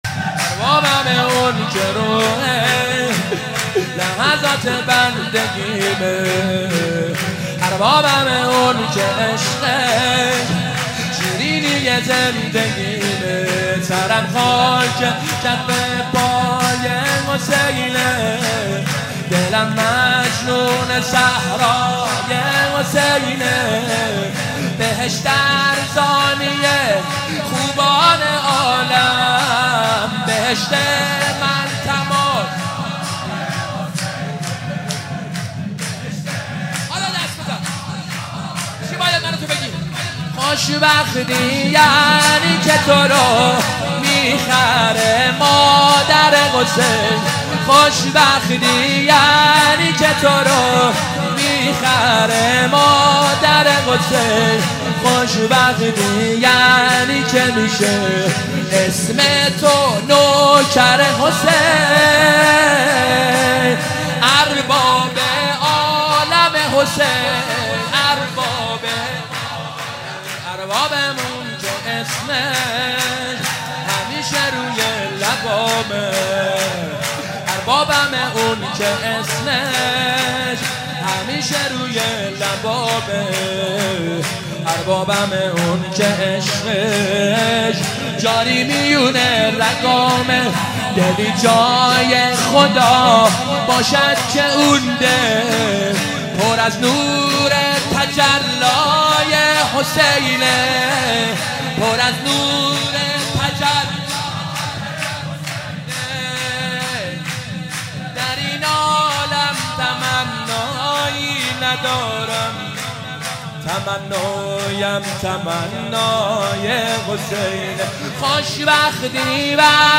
مداحی
ولادت حضرت عباس (ع)